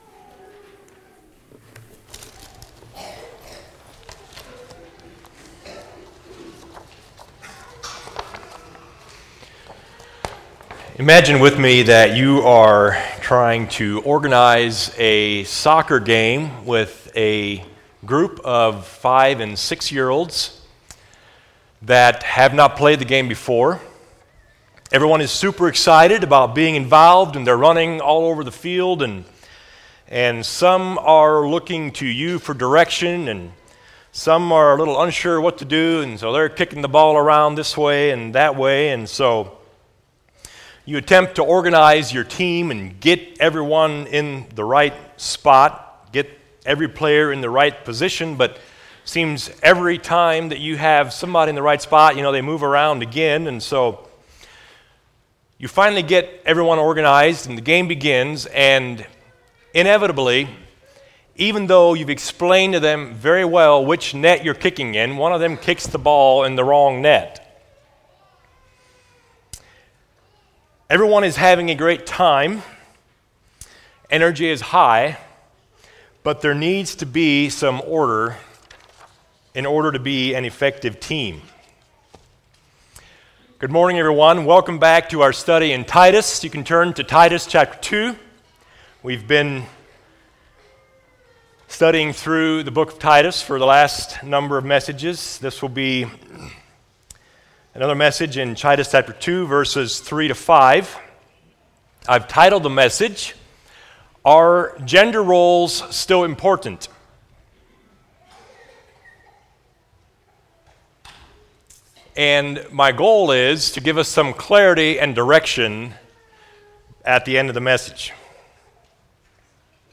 Home Sermons Are Gender Roles Important?